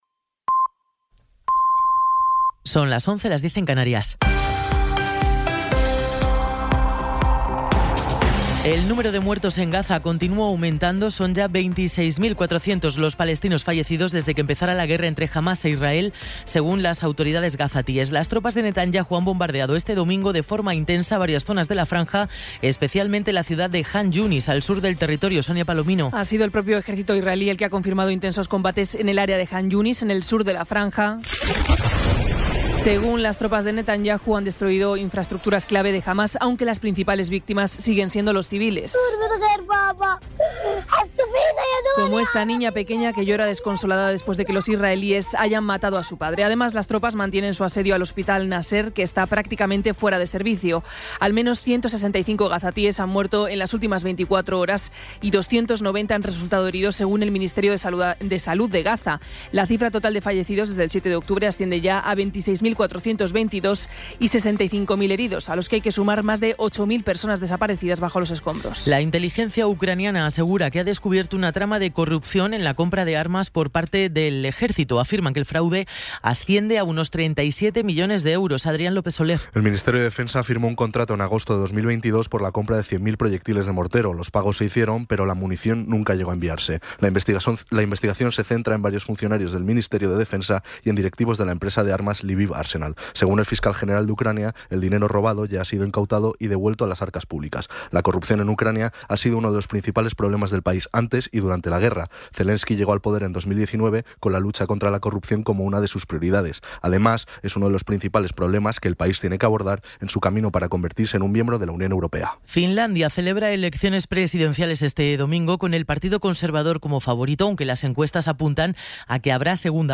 Resumen informativo con las noticias más destacadas del 28 de enero de 2024 a las once de la mañana.